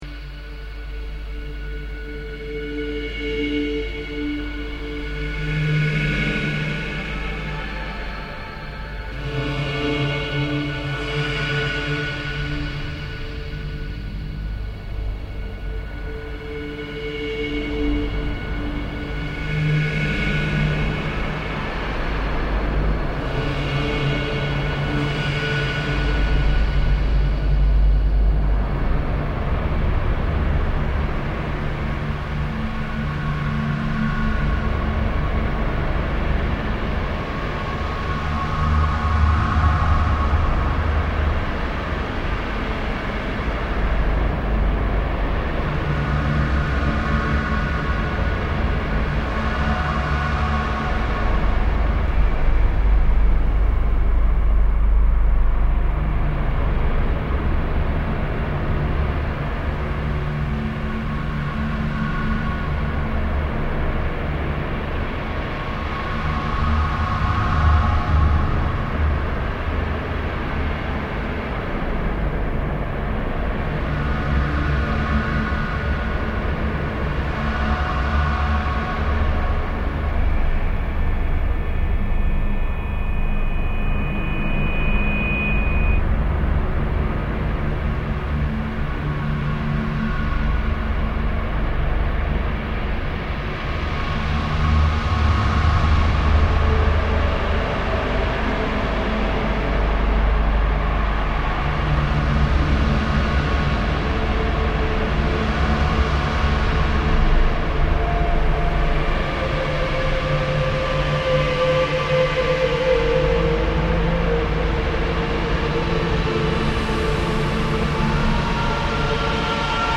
FILMTEXT: AN ORIGINAL WWW SOUNDTRACK is an experimental performance for spoken word and electronics that utilizes a small cluster of PC Laptops, mixing board, portable MiniDisc recorders, portable cd players, scanners, various delay and Midi-verb units, a Sherman FilterBank, and microphones.
Methodically pursuing a practice of surf-sample-manipulate, the artists use all possible digital source material at their disposal to create an on-the-fly sound narrative remix.
Lucerne (Inside the Grand National Hotel) (2.3 MB)